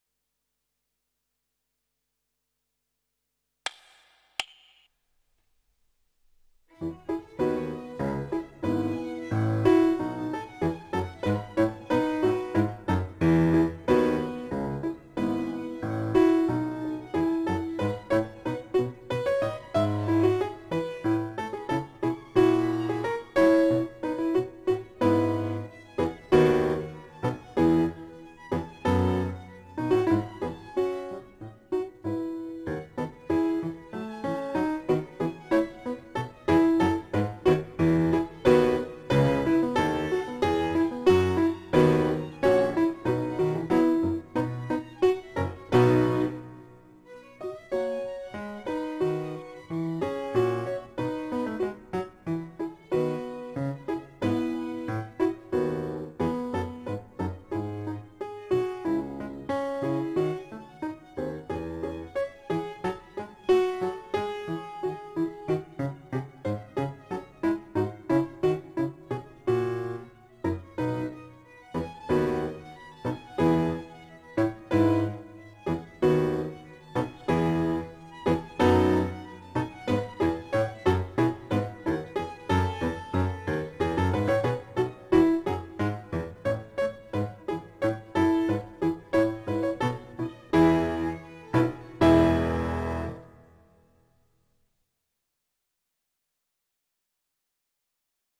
0012-享德尔第六小提琴奏鸣曲2.mp3